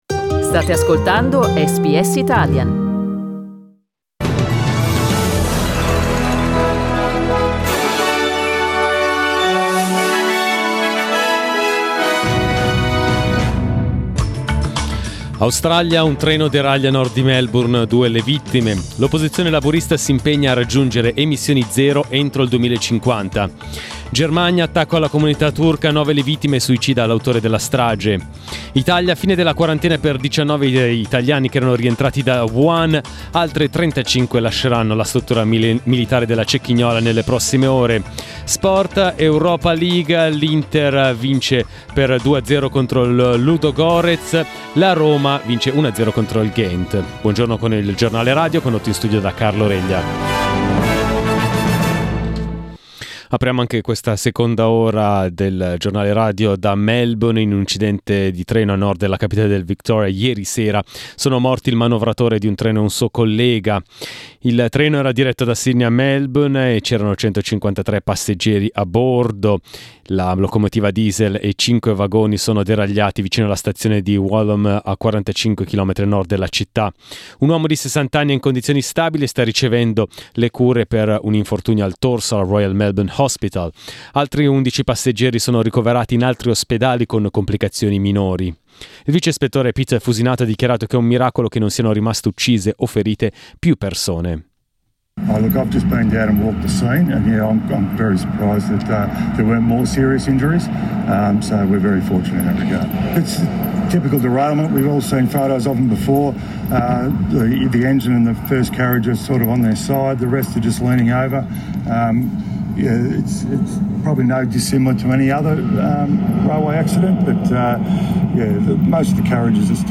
Our news bulletin in Italian.